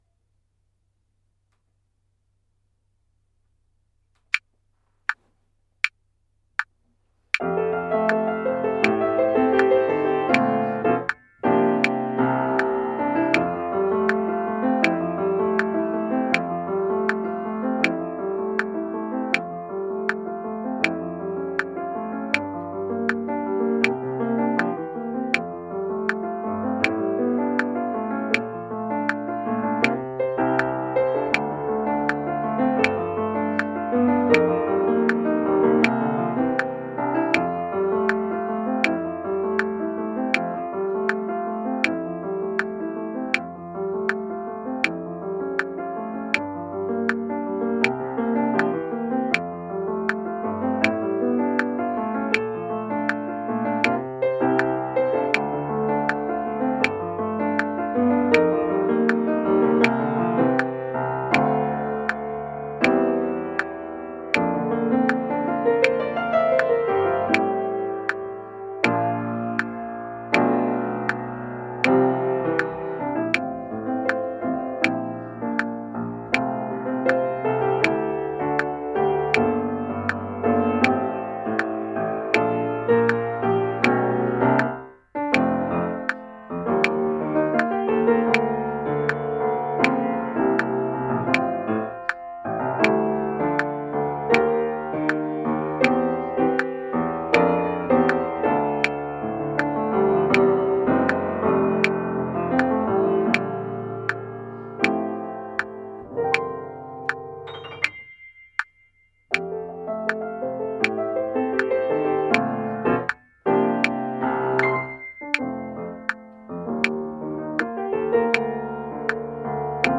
Piano & click audio (clicks on beats 1 and 3)
piano_click.mp3